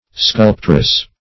Search Result for " sculptress" : Wordnet 3.0 NOUN (1) 1. a woman sculptor ; The Collaborative International Dictionary of English v.0.48: Sculptress \Sculp"tress\, n. A female sculptor.